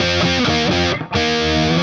AM_HeroGuitar_130-F01.wav